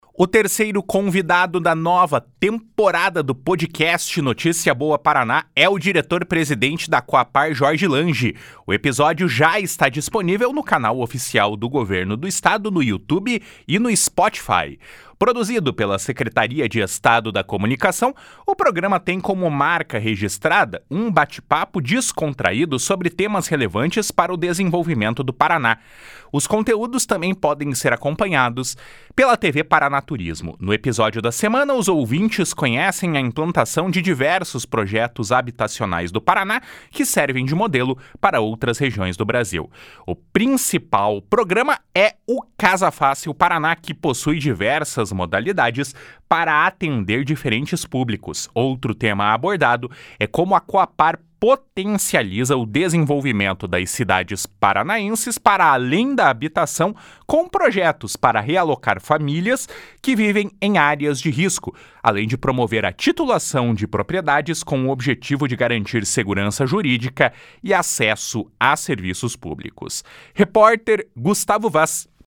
O terceiro convidado da nova temporada do podcast Notícia Boa Paraná é o diretor-presidente da Cohapar, Jorge Lange.
Produzido pela Secretaria de Estado da Comunicação, o programa tem como marca registrada um bate-papo descontraído sobre temas relevantes para o desenvolvimento do Paraná.